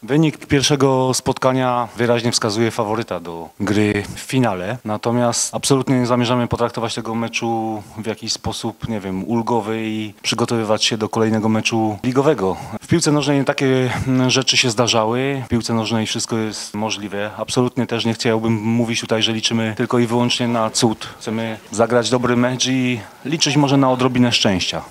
Przed Pogonią trudne zadanie, ale trener Kazimierz Moskal na konferencji prasowej przypominał, że w piłce nożnej wszystko jest możliwe.